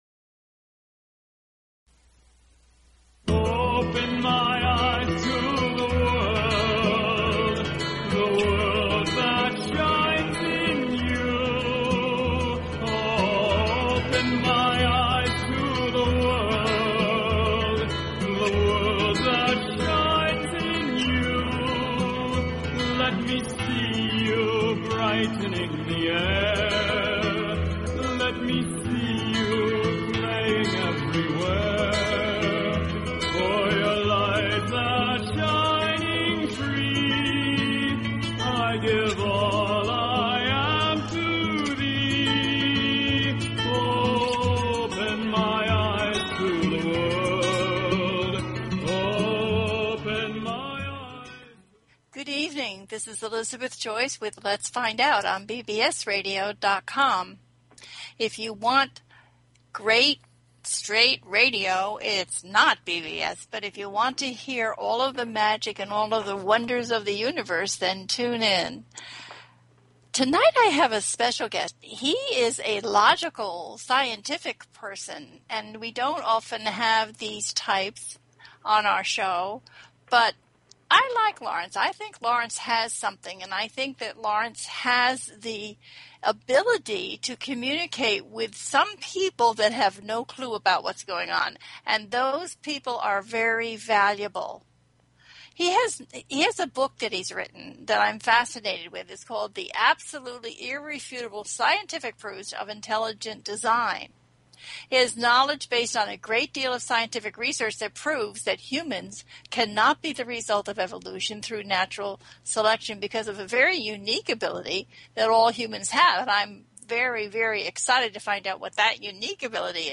Talk Show Episode
The listener can call in to ask a question on the air.